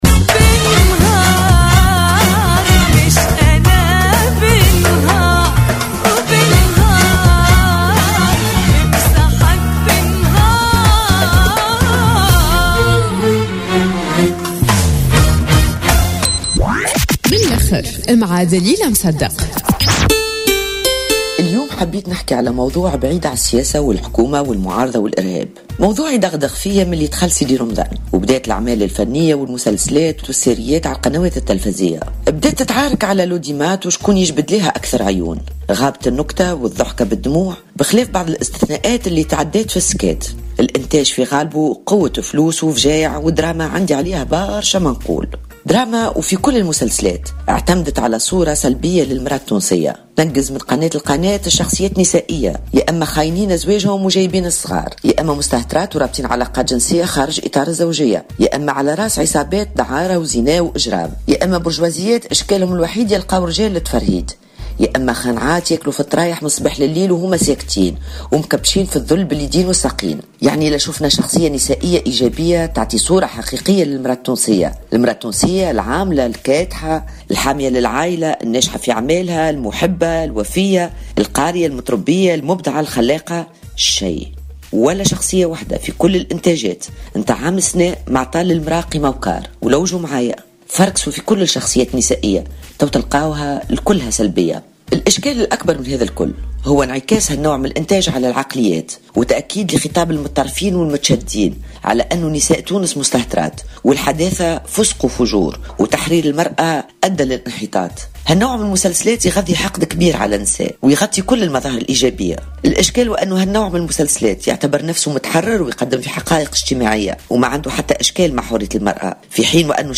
في حوار خاص مع إذاعة جوهرة أف أم اليوم الخميس 09 جويلية، أكد رئيس حركة النهضة الشيخ راشد الغنوشي إن الوضع وصل في تونس في وقت ما إلى وضع متأزم وخاصة في شهر رمضان منذ سنتين حين شهدت البلاد اعتصامين في ساحة باردو، أي اعتصامي الرحيل والصمود، في حين كان المجلس التأسيسي أنذاك معطلا حيث اقتربت البلاد حينها من السيناريو المصري ومن "الارتداد على الديمقراطية" وفق تعبيره.